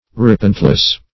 Repentless \Re*pent"less\